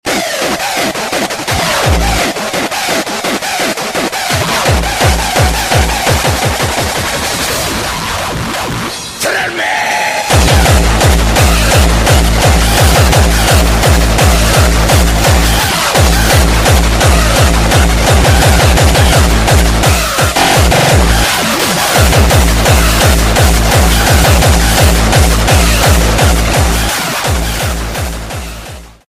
Dance & Trance